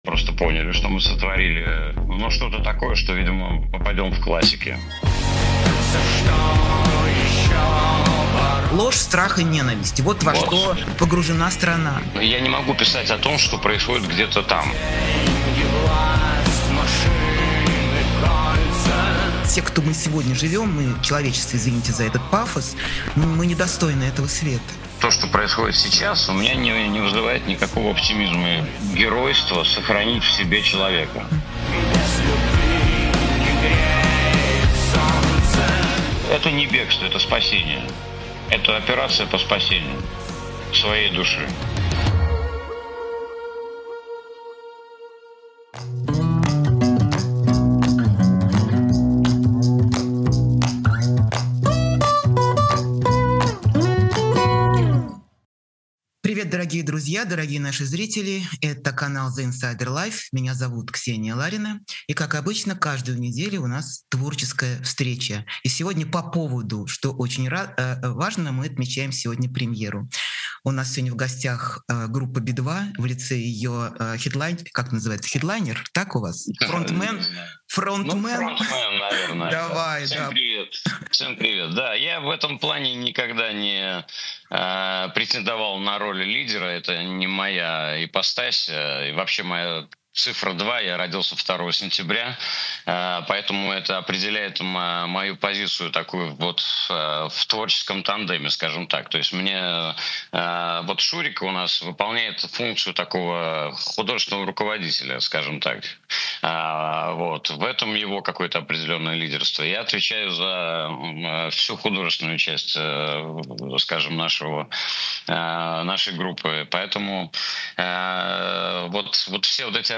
Эфир ведёт Ксения Ларина
Гость — Лёва Би-2.